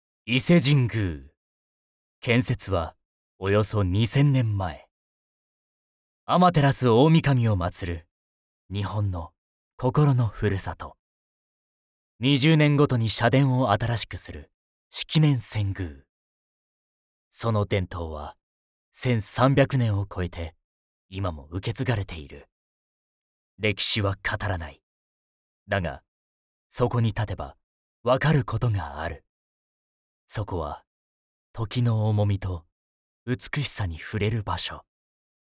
優しい声から厚みのある声まで幅広い声質で、感性豊かに、さまざまな場面に対応できます。
– ナレーション –
堂々とした、厳かな